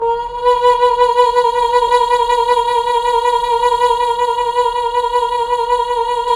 Index of /90_sSampleCDs/Roland LCDP09 Keys of the 60s and 70s 1/KEY_Chamberlin/VOX_Chambrln Vox